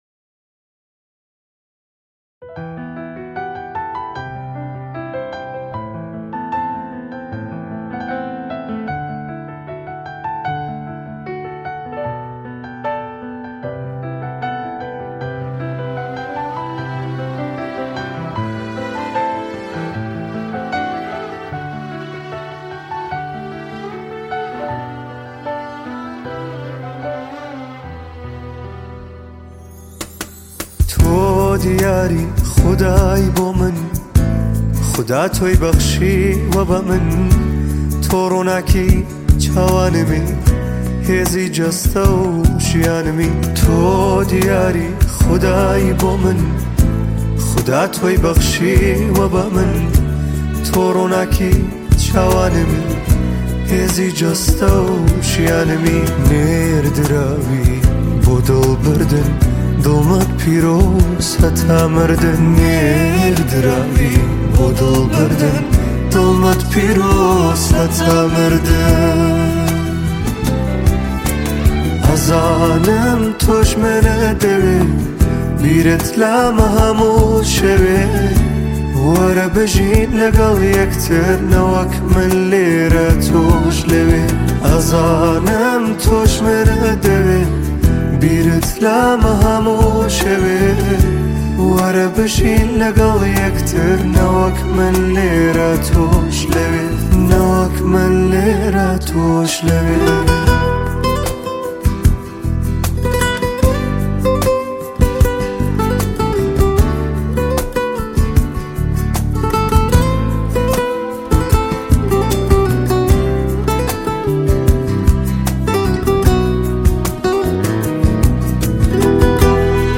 آهنگ کوردی